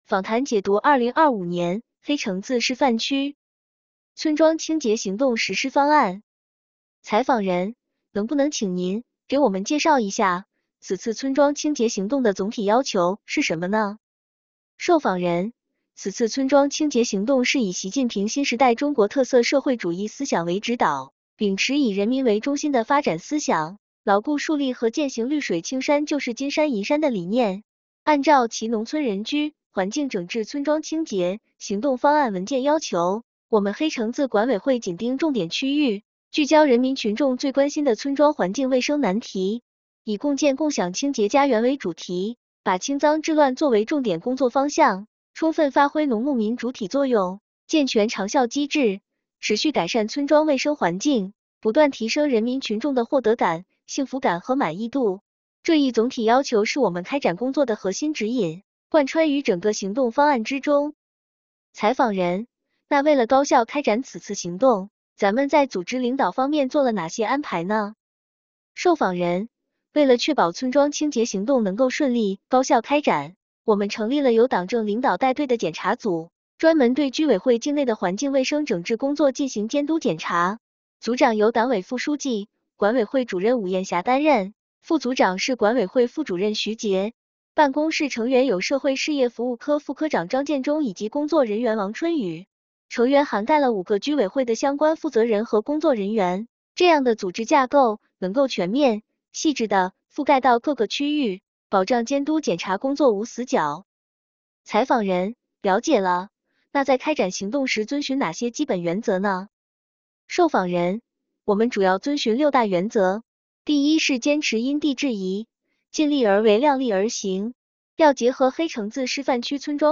【访谈解读】2025年黑城子示范区村庄清洁行动实施方案.mp3